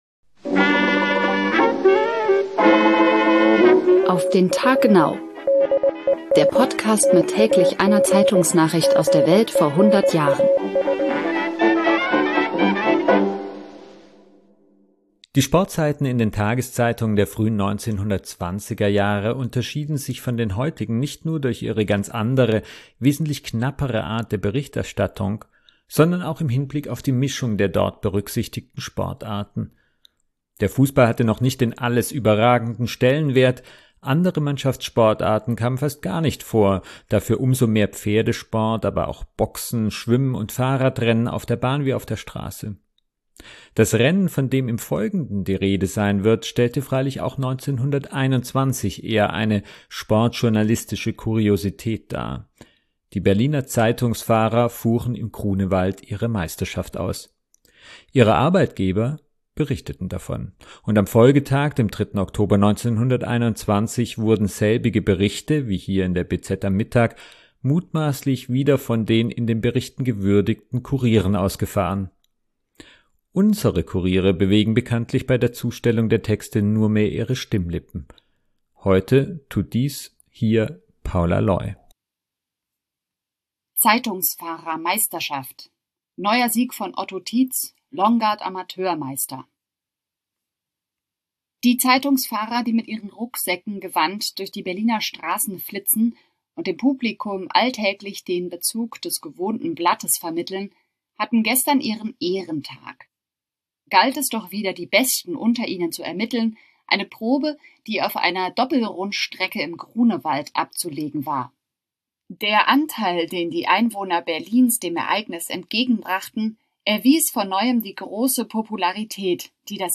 Unsere